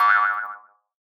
Cartoon Boing
boing cartoon field recording sound effect free sound royalty free Movies & TV